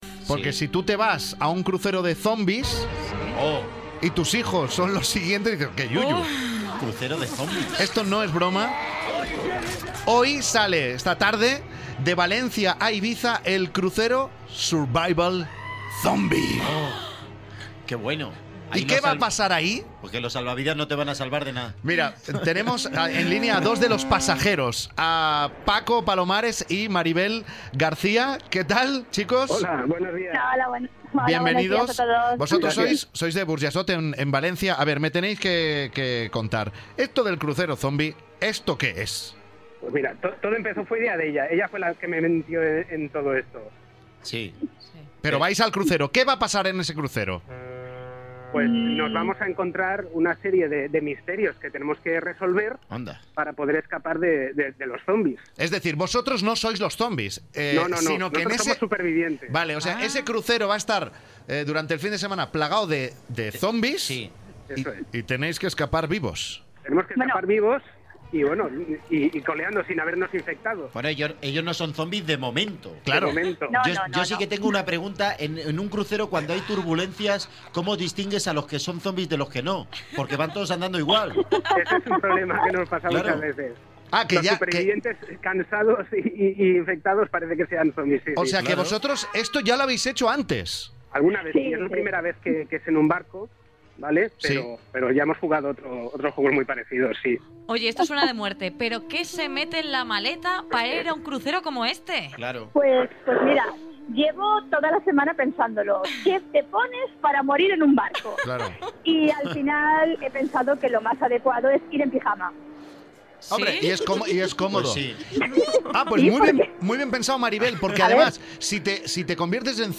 Frank Blanco nos cuenta que zarpa el crucero zombie desde Valencia